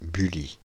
Bully (French pronunciation: [byli]
Fr-Paris--Bully.ogg.mp3